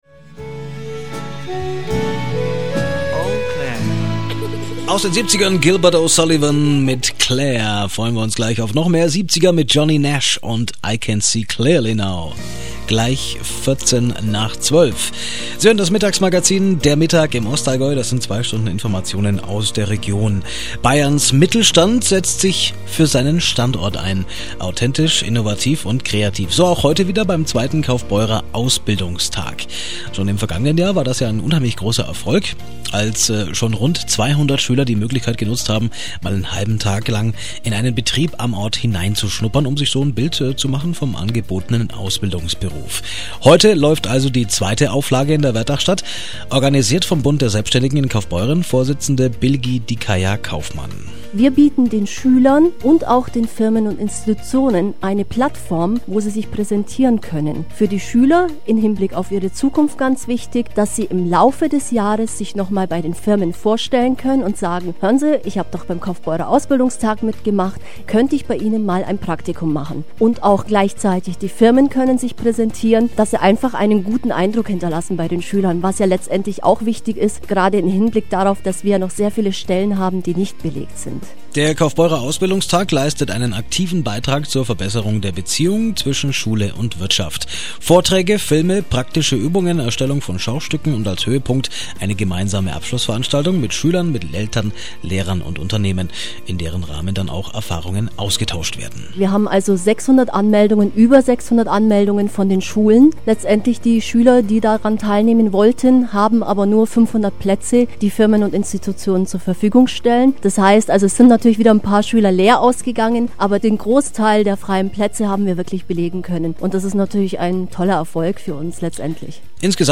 RSA-Radiointerview zum Kaufbeurer Ausbildungstag 2010
radiomitschnitt_um_kaufbeurer_ausbildungstag.mp3